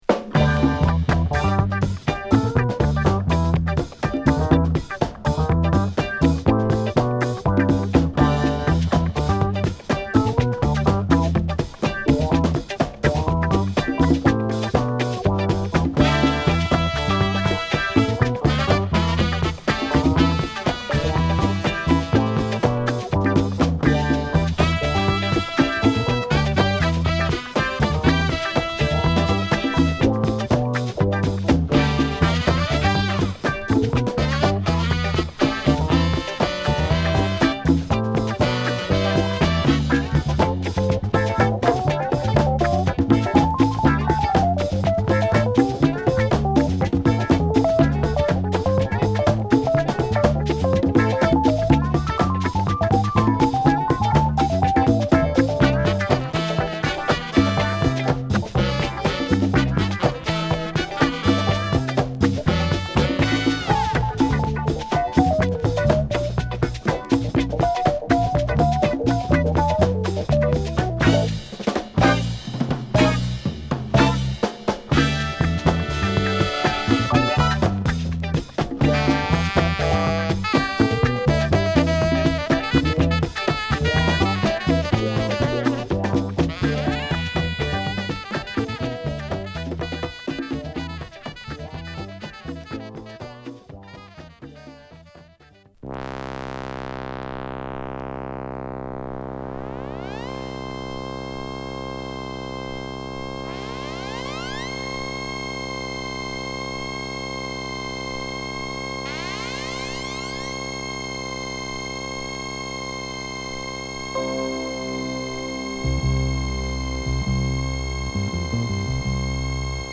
大御所パーカッション奏者
いつもよりもソウル〜レアグルーヴ感の強い1枚です！